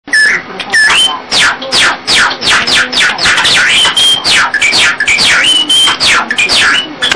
↓ ※音が小さいです ↓
ノロの応援歌 　・・・意外とリズミカルなノロのさえずり
擬音化「チュッピ ・チュッピ、ピー・ピー・ピーピィピィヨン・ピーピーピー（句読点以後の繰り返し）